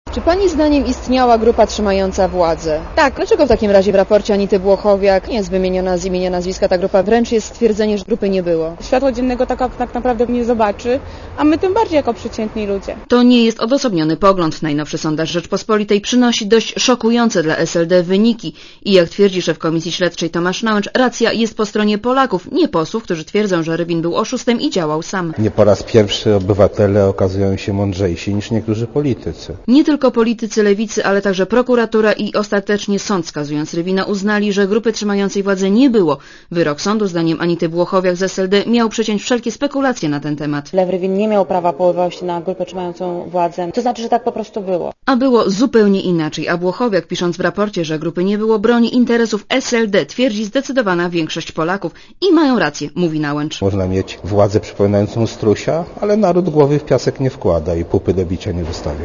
Posłuchaj relacji reporterki Radia ZET (229 KB)